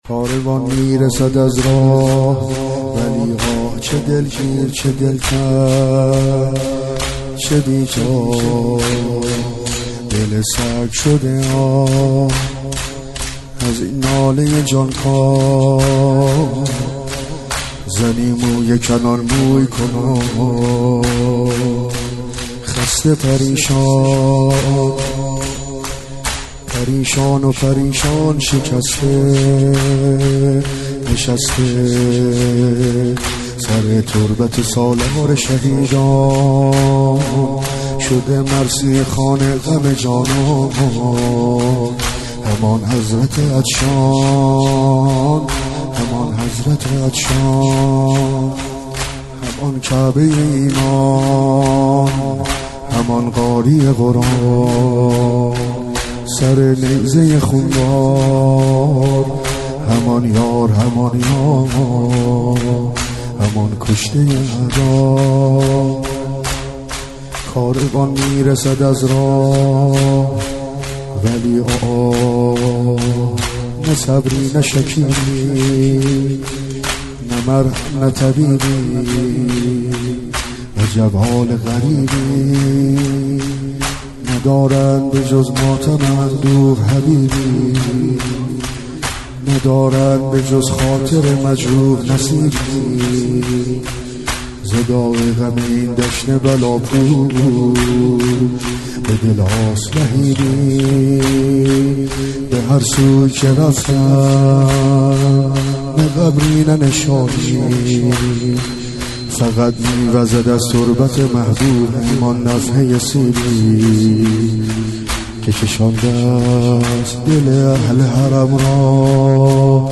مناسبت : اربعین حسینی